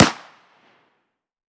ACE3 / extras / assets / CookoffSounds / shotbullet / close_1.wav
Cookoff - Improve ammo detonation sounds